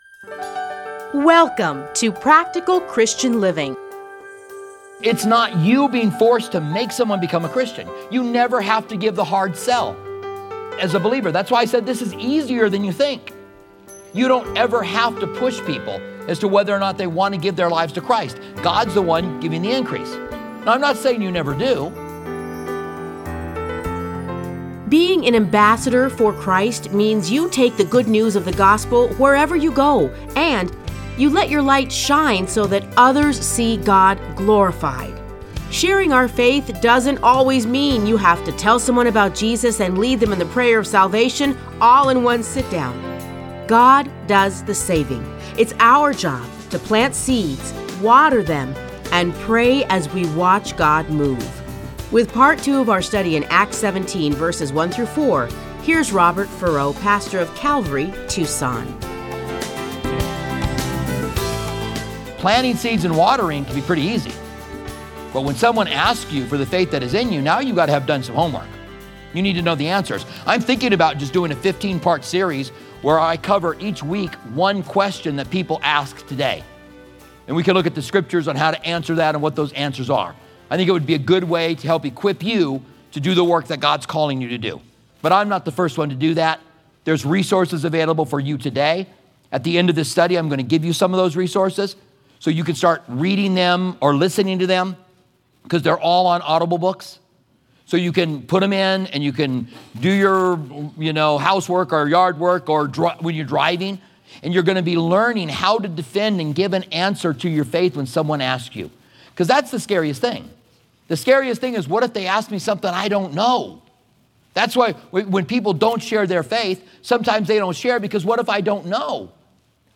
Listen to a teaching from Acts 17:1-4.